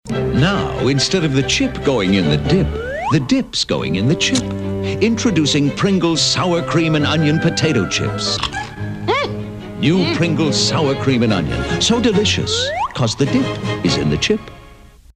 Radio-Commercials-1980s-Pringles-Chips-Audio-Enhanced.mp3